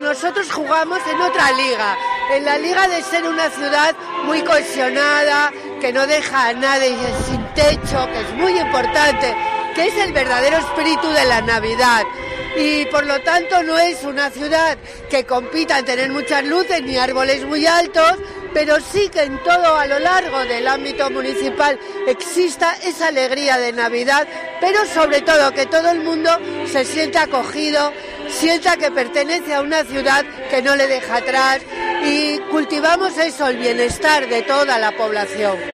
Declaraciones de Mariví Monteserín, alcaldesa de Avilés